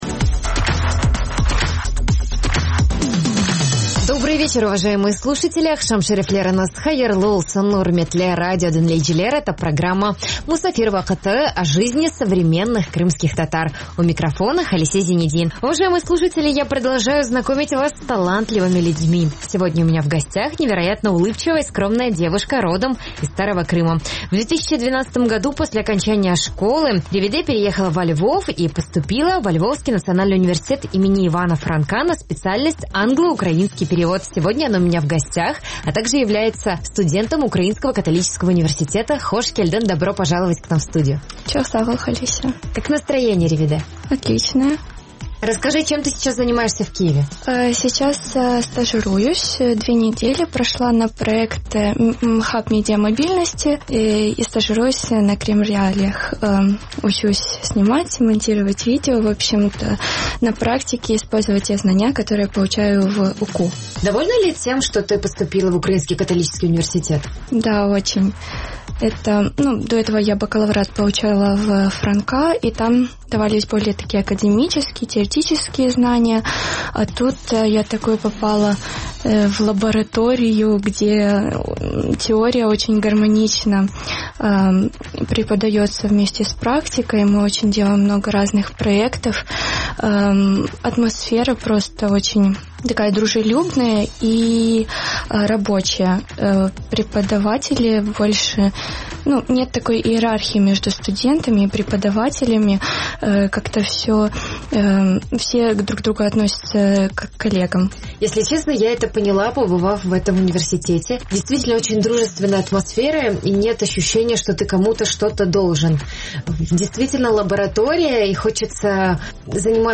26 августа в эфире программы «Musafir vaqtı» говорят о студенческой и творческой жизни во Львове.